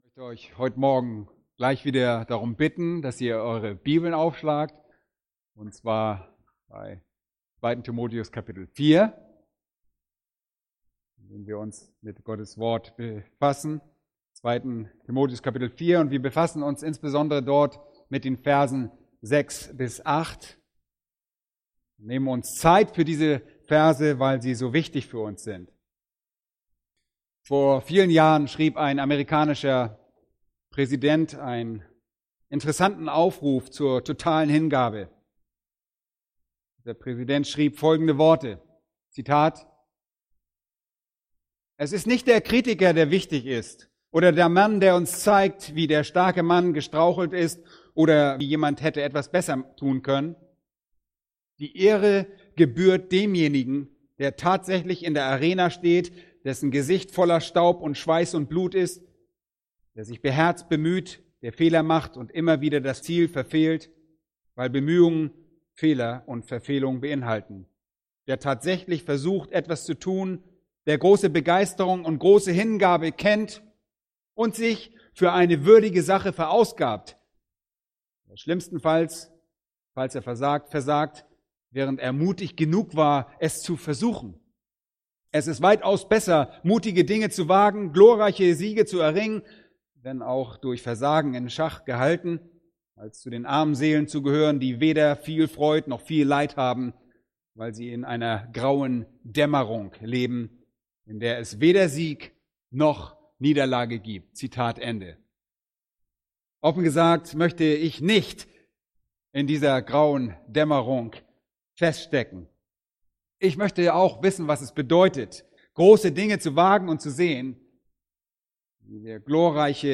Predigt: "1.